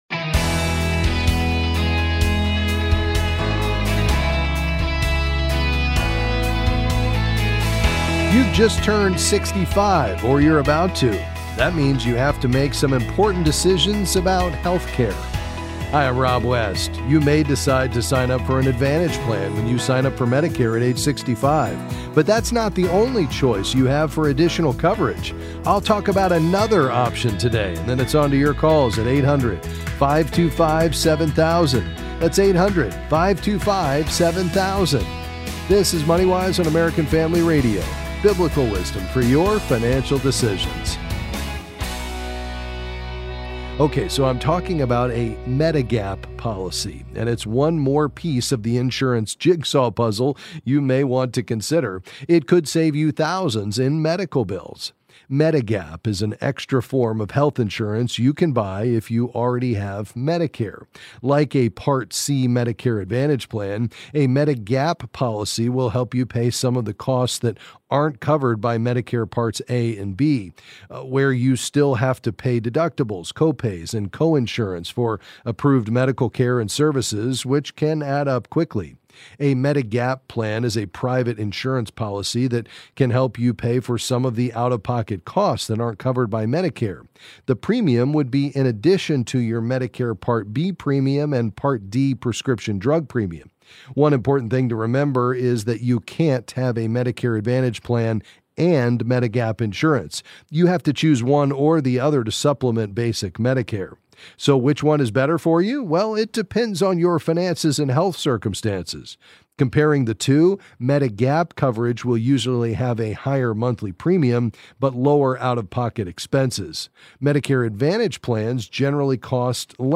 Then he’ll answer some calls on a variety of financial topics.